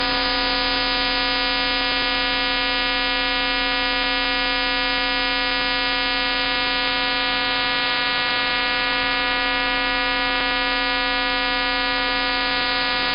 Fmcw_240Hz_2usb.mp3